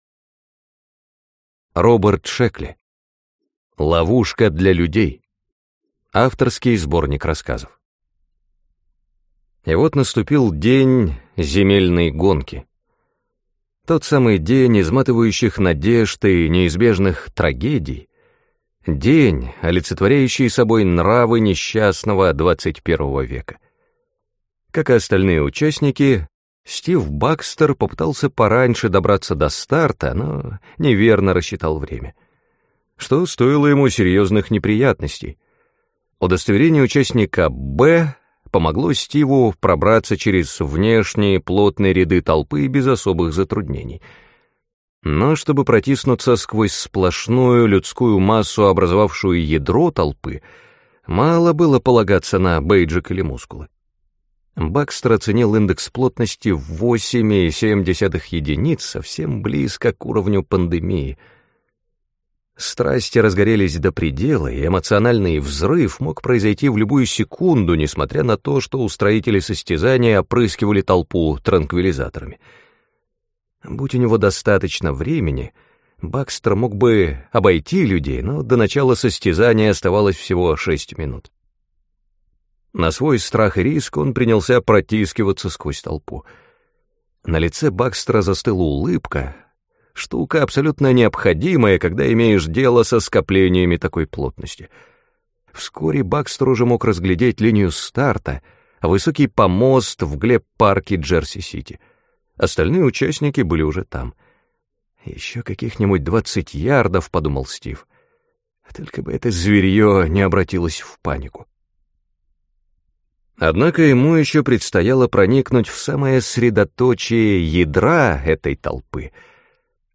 Аудиокнига Ловушка для людей (сборник) | Библиотека аудиокниг